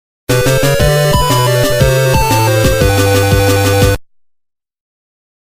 win/lose jingle
Self-recorded